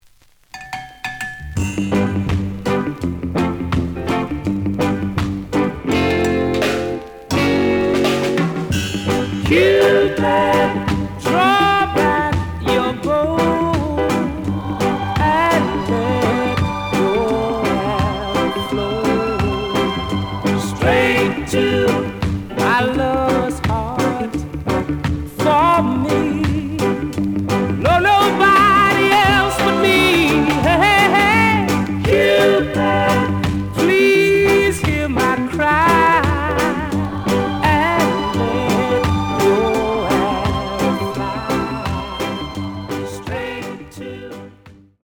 The audio sample is recorded from the actual item.
●Genre: Rock Steady